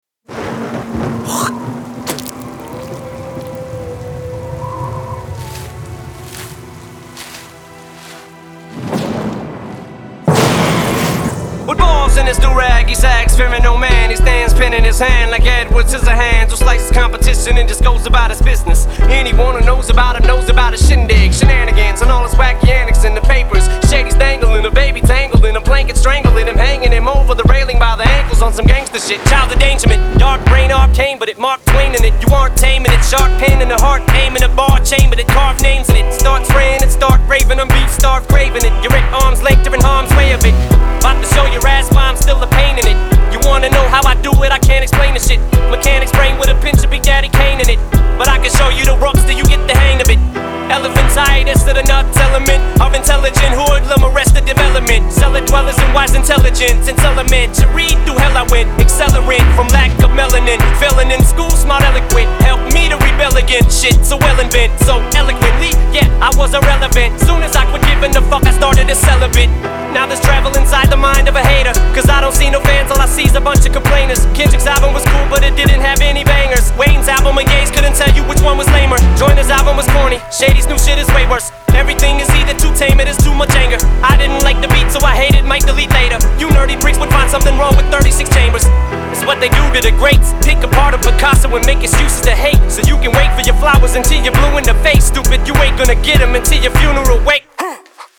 Hip Hop, Rap